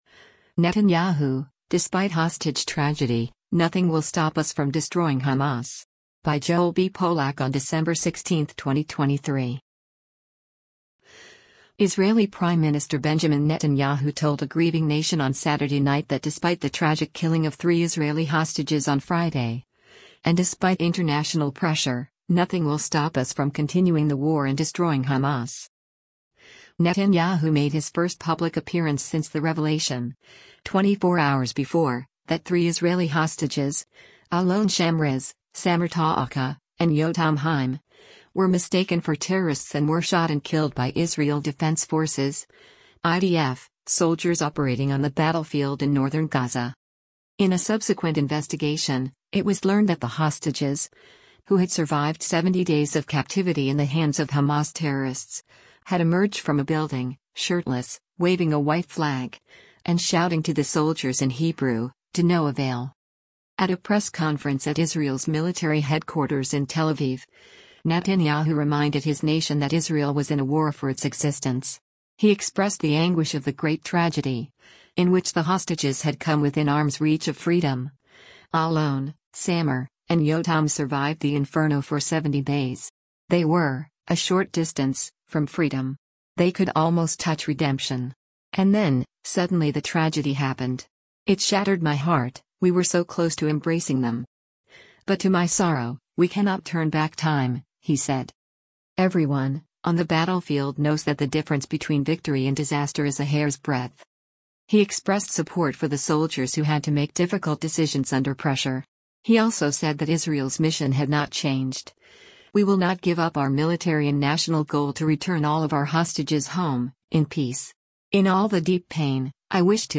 Benjamin Netanyahu press conference on hostages (Screenshot / Youtube)
At a press conference at Israel’s military headquarters in Tel Aviv, Netanyahu reminded his nation that Israel was in a war for its existence.